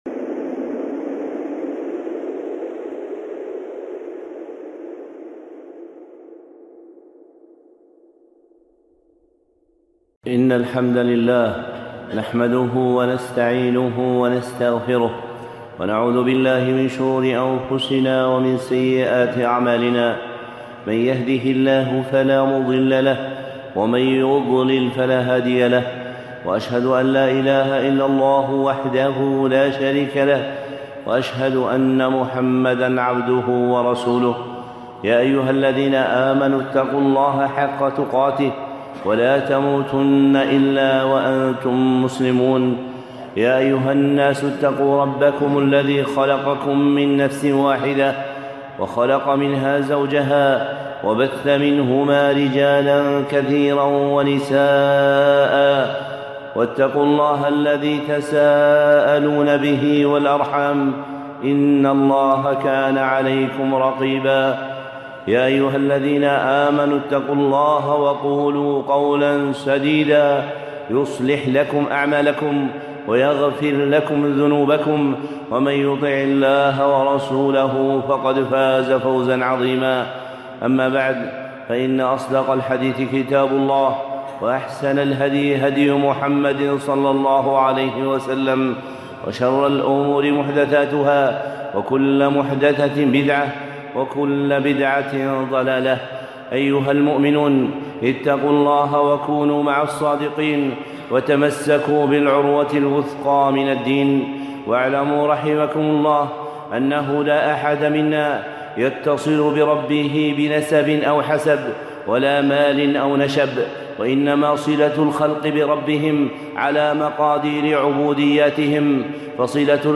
خطبة (تقوية العبودية بذكر الله)